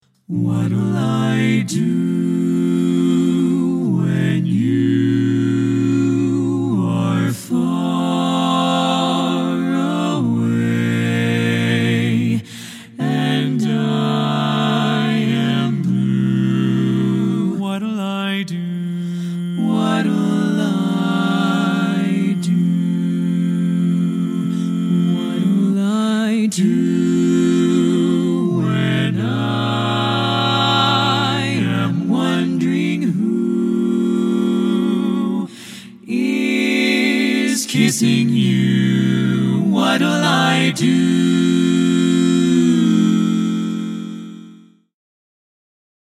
Choral Jazz
Four-part a cappella harmony SATB arrangement.
SATB A Cap